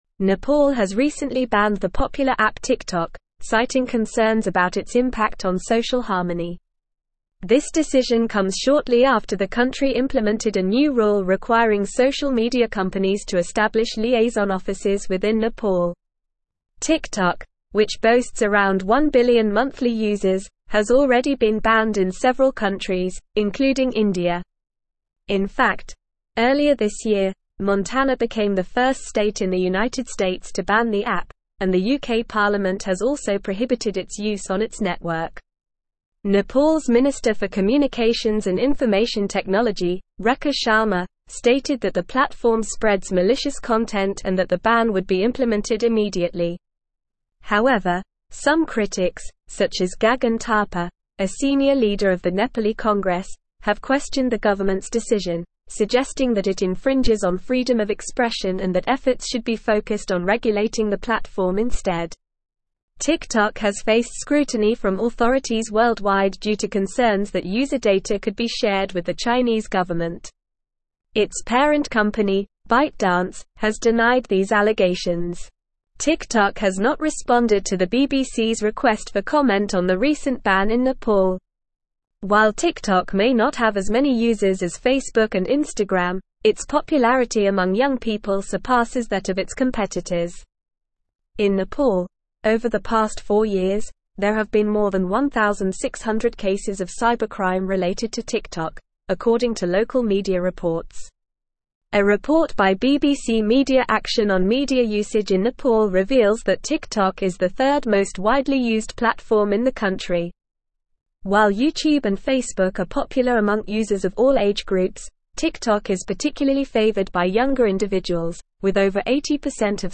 Normal
English-Newsroom-Advanced-NORMAL-Reading-Nepal-Bans-TikTok-Over-Social-Harmony-Concerns.mp3